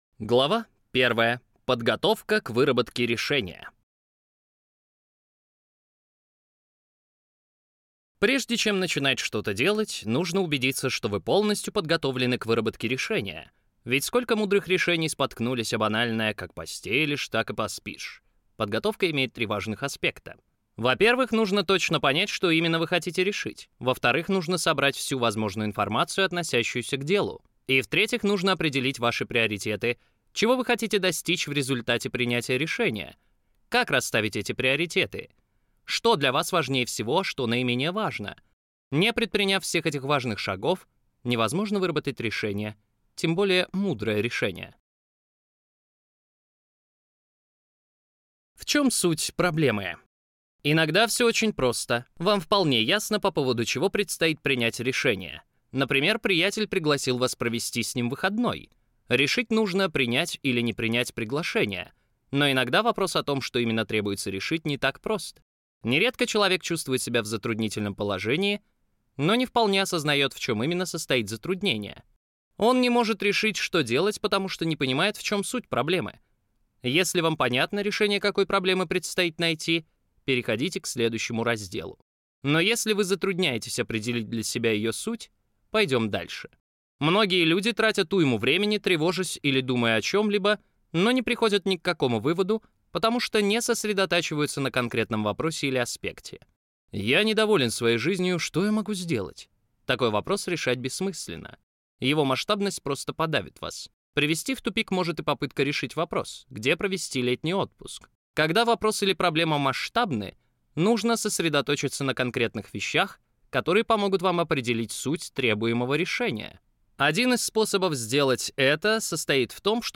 Аудиокнига Что делать, когда не знаешь, что делать | Библиотека аудиокниг